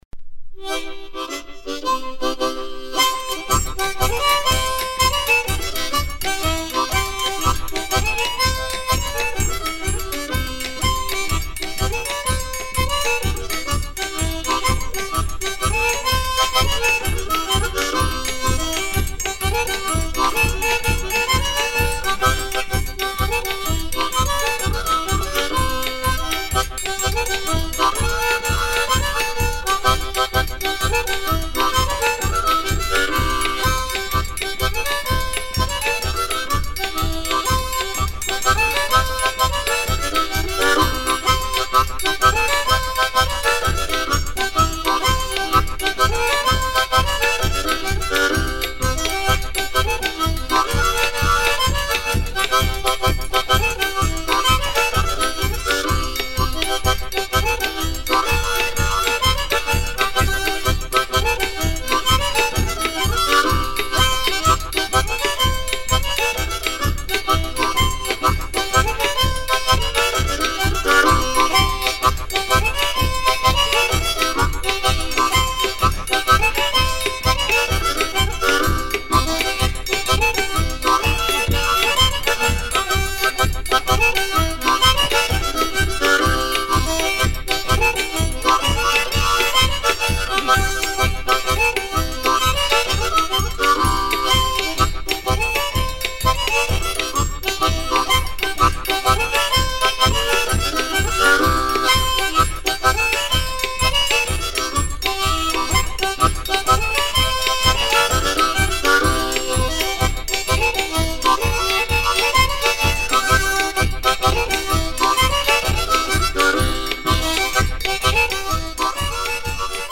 danse : contredanse